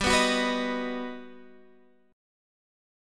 G+M7 chord